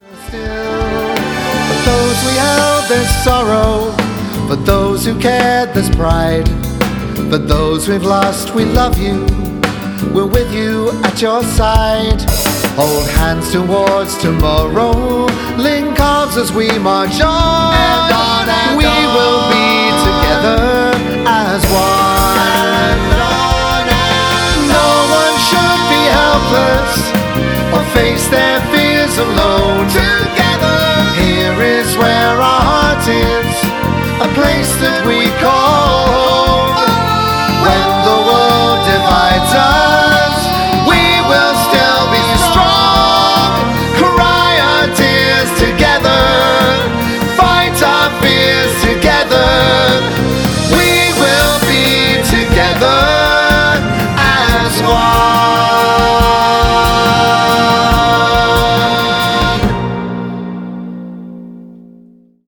These are performed in similar styles to the finished versions but have different orchestrations and keys.